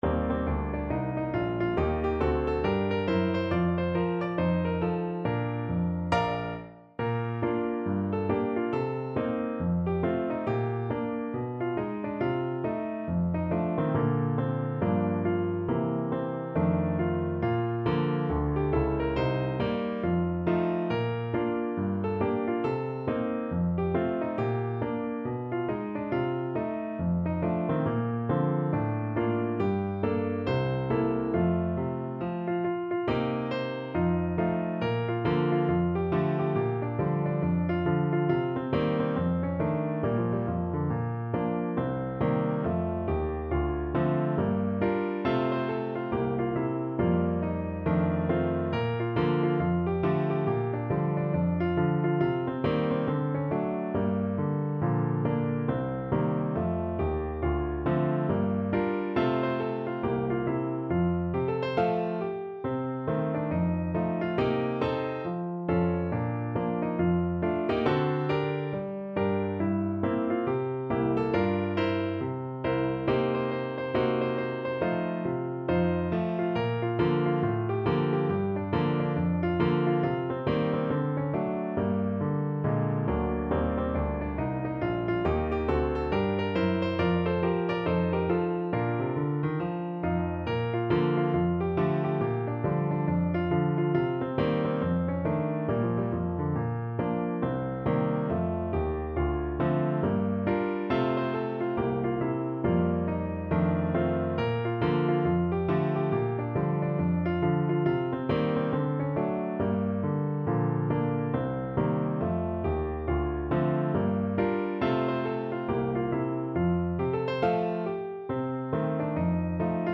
With a swing =c.69
Pop (View more Pop Voice Music)